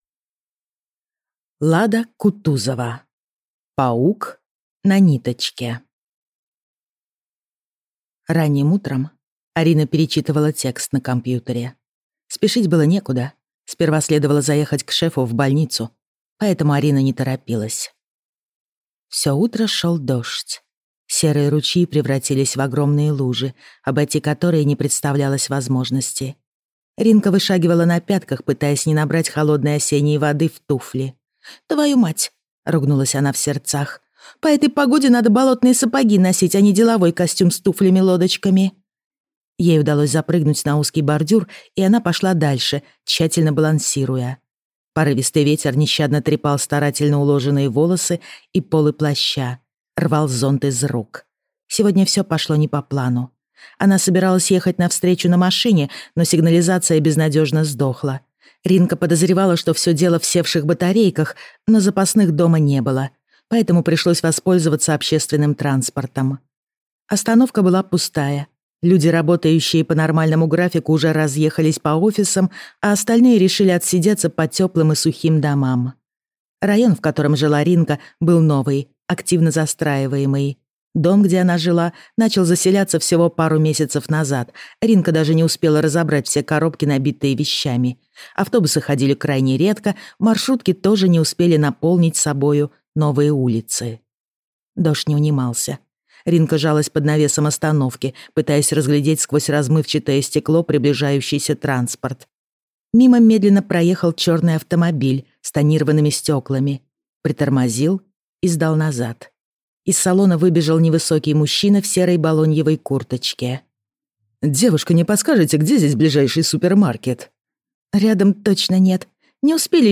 Аудиокнига Паук на ниточке | Библиотека аудиокниг